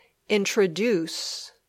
Click on each word to hear it and practice its pronunciation.